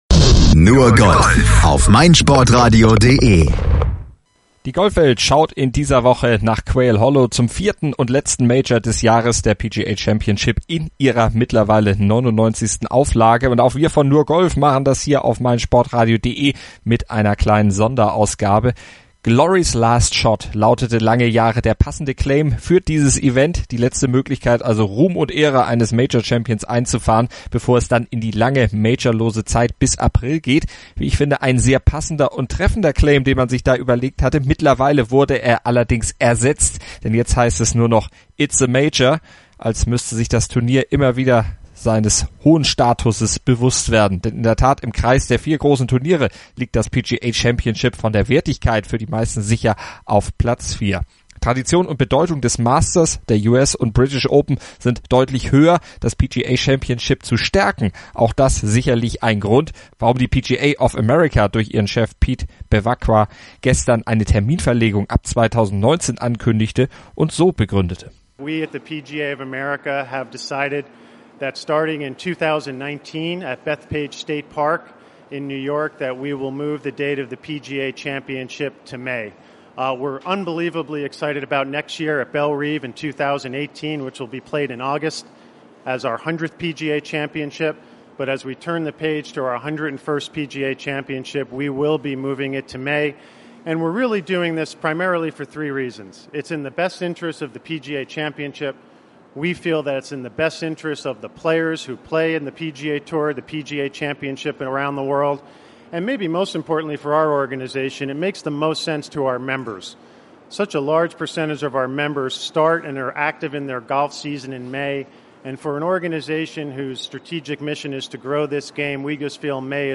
lässt die Verantwortlichen im Originalton Stellung nehmen